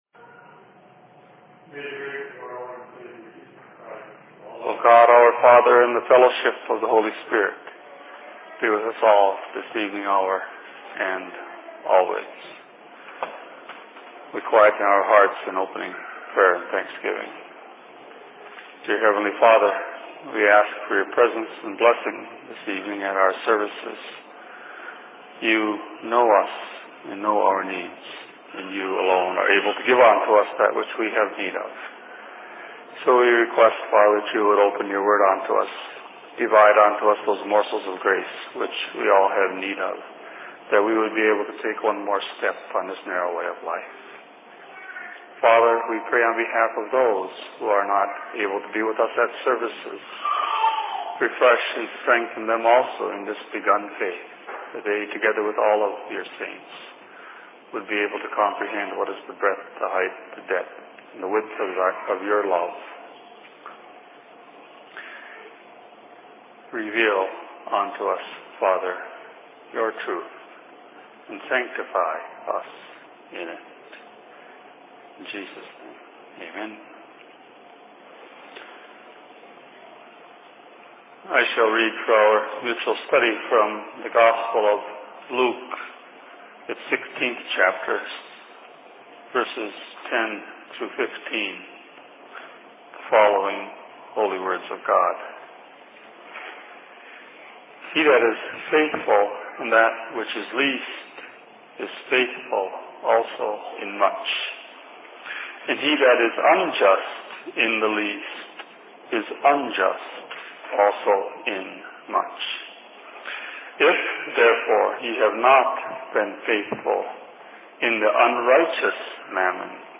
Sermon in Minneapolis 13.08.2006